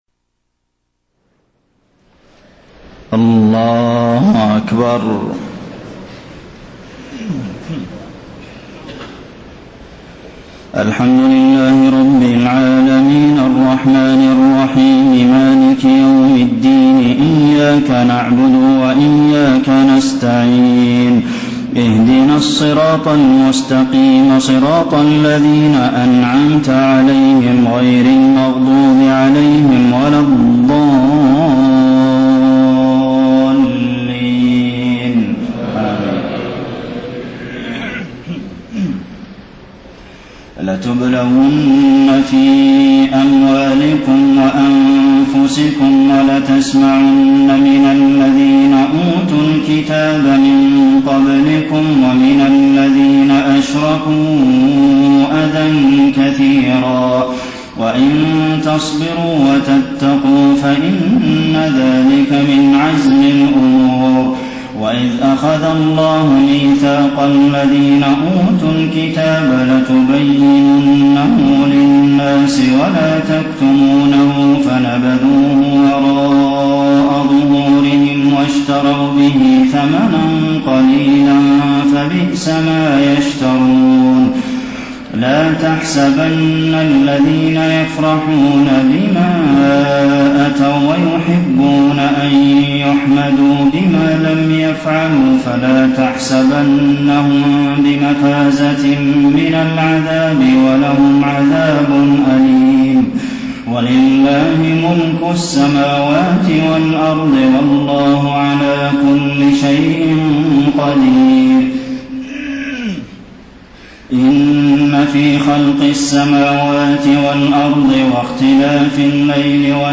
تهجد ليلة 24 رمضان 1433هـ من سورتي آل عمران (186-200) و النساء (1-22) Tahajjud 24 st night Ramadan 1433H from Surah Aal-i-Imraan and An-Nisaa > تراويح الحرم النبوي عام 1433 🕌 > التراويح - تلاوات الحرمين